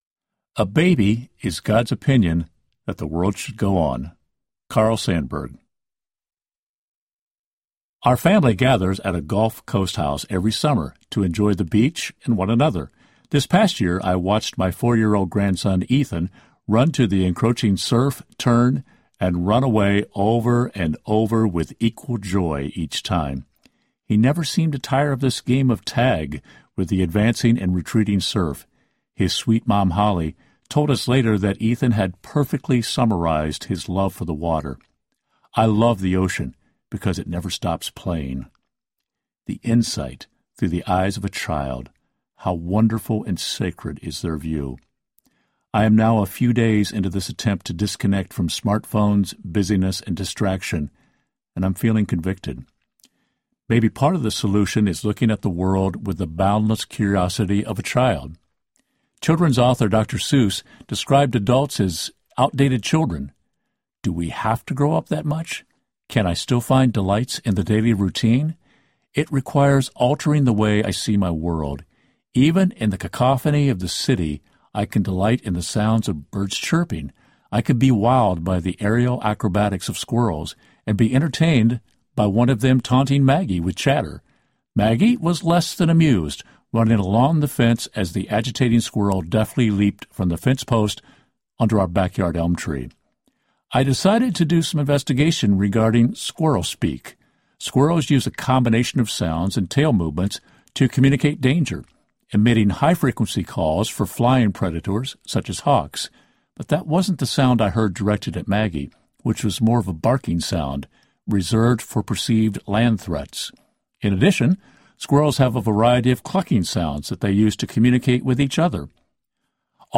Waking Up Slowly Audiobook